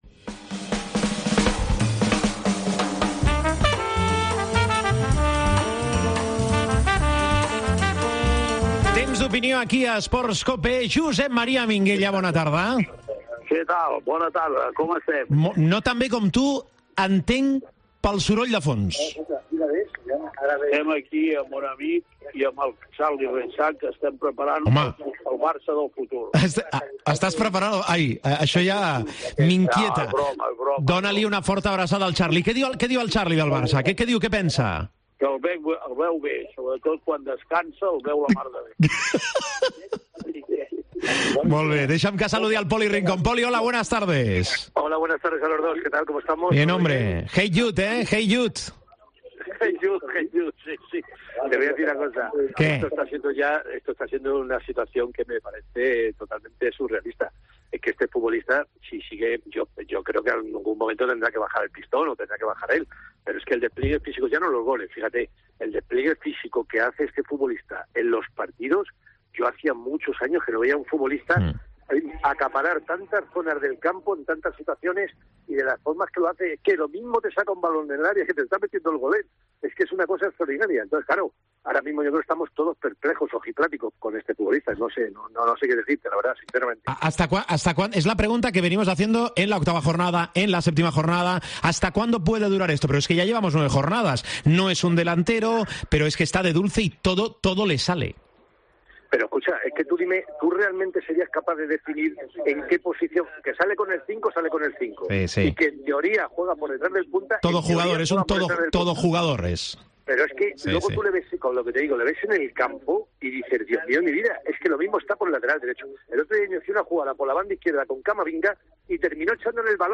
El debat Esports COPE, amb Poli Rincón i Minguella
AUDIO: Els dos col·laboradors de la Cadena COPE repassen l'actualitat esportiva d'aquesta setmana.